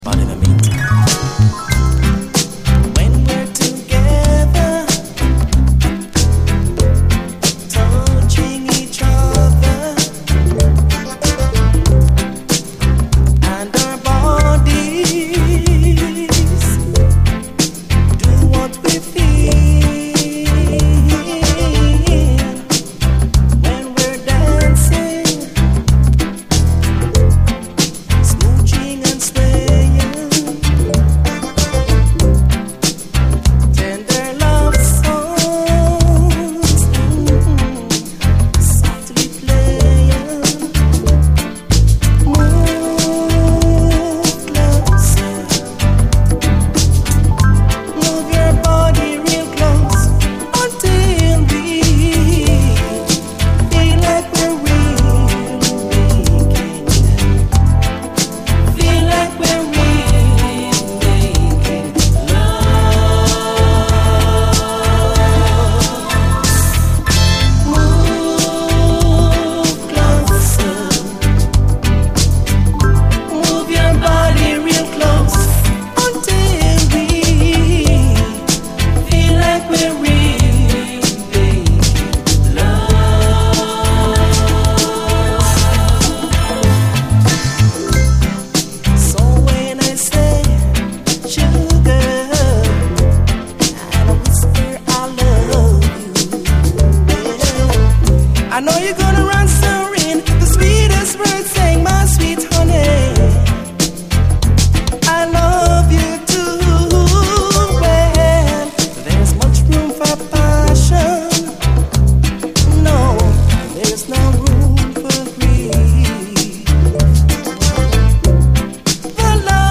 非常に今日的なライトなシンセ・アレンジの最高メロウ・ディスコ・レゲエ